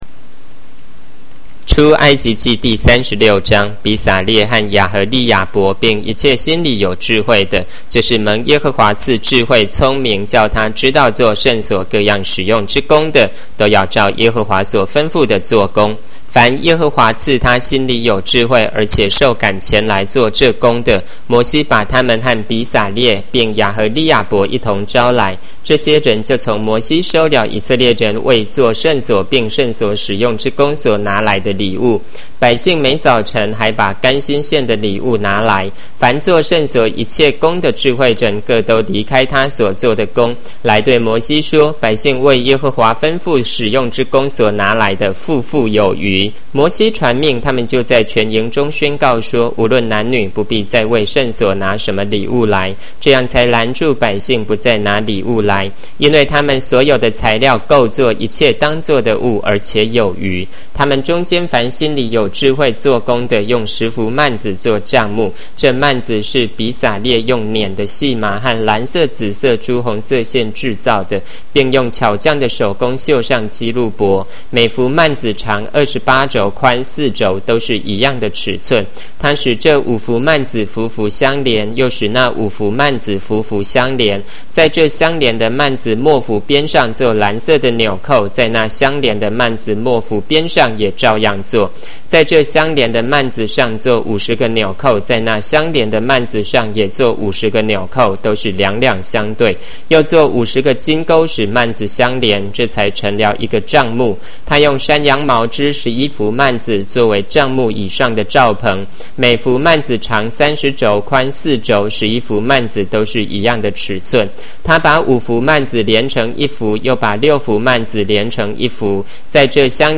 Spring 版和合本有聲聖經